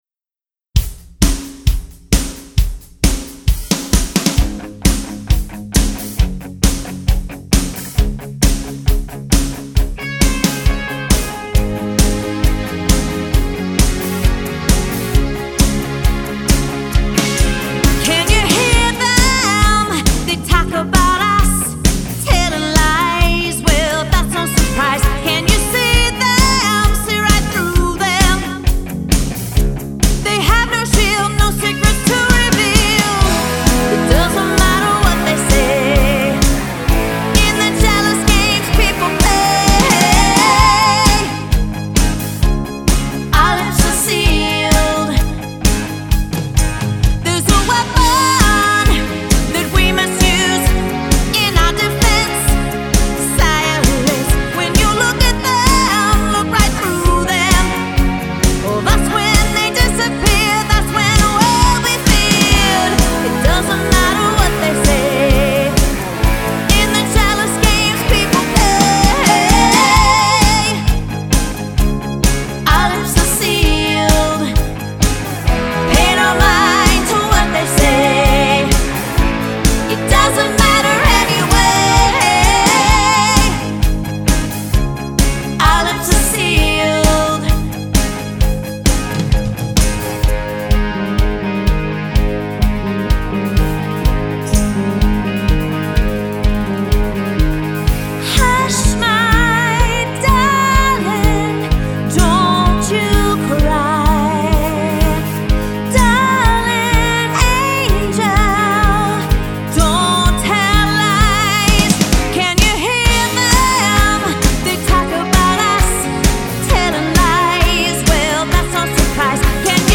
80’s band